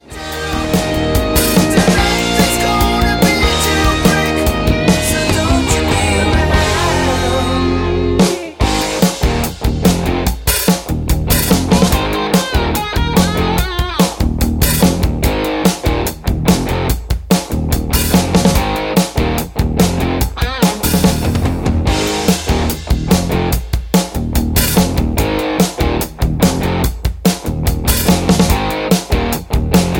MPEG 1 Layer 3 (Stereo)
Backing track Karaoke
Rock, 1970s